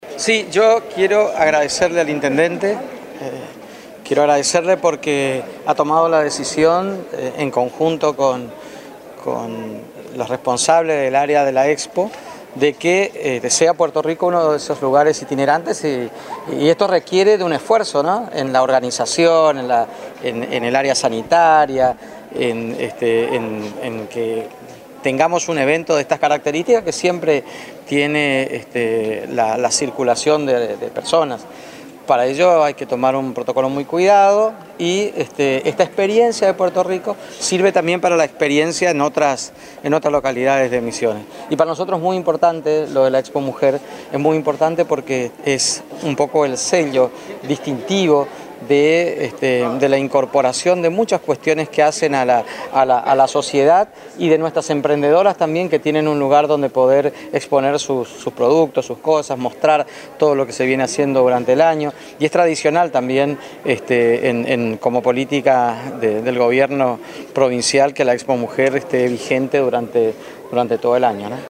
Al final de la recorrida atendió a los periodistas y comunicadores, entre otros conceptos acerca de la «Expo Mujer» dijo: